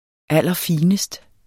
Udtale [ ˈalˀʌˈfiːnəsd ]